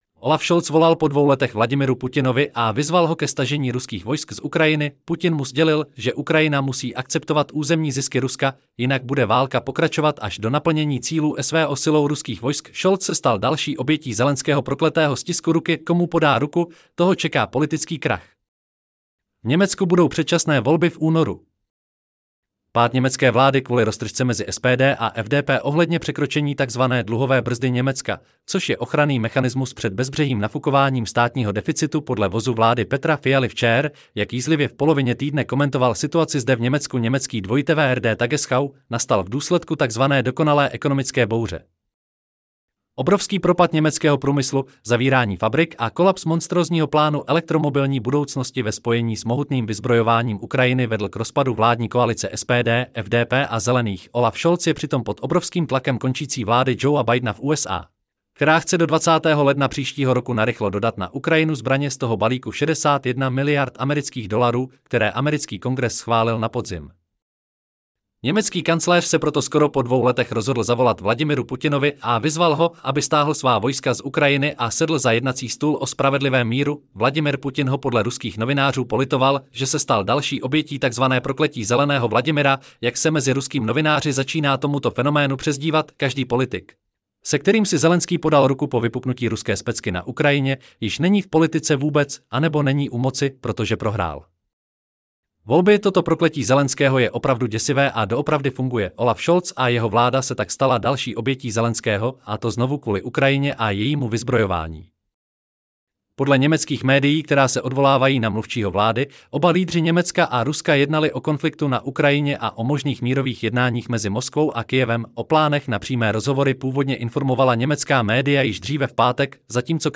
Celý článek si můžete poslechnout v audioverzi zde: Olaf-Scholz-volal-po-dvou-letech-Vladimiru-Putinovi-a-vyzval-ho-ke-stazeni-ruskych-vojsk-z 16.11.2024 Olaf Scholz volal po 2 letech Vladimiru Putinovi a vyzval ho ke stažení ruských vojsk z Ukrajiny!